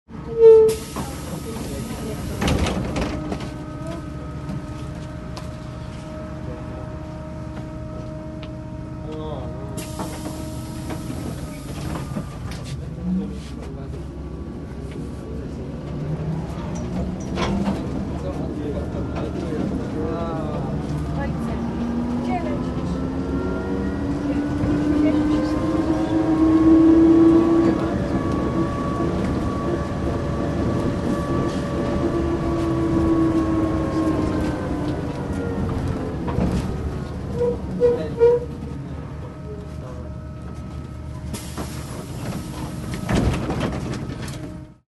Звуки троллейбуса
На этой странице собраны звуки троллейбуса: от плавного старта до равномерного движения с характерным гулом.